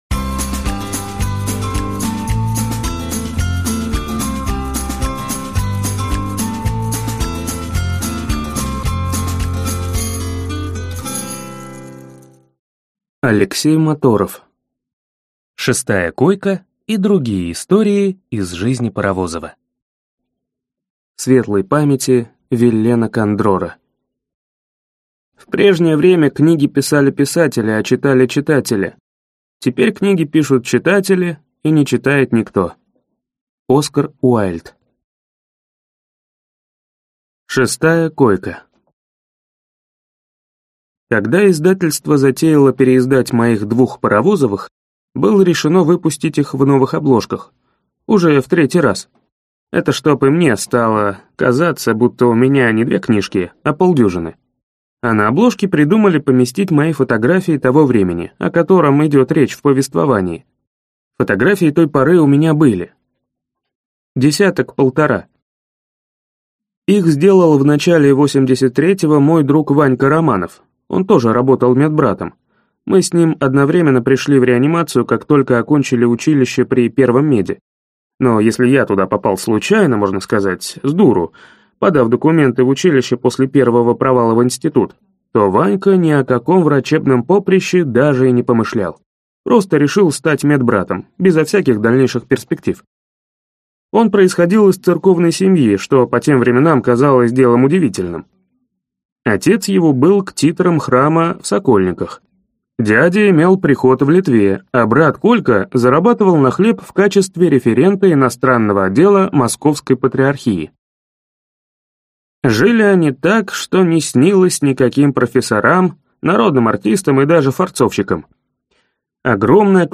Аудиокнига Шестая койка и другие истории из жизни Паровозова | Библиотека аудиокниг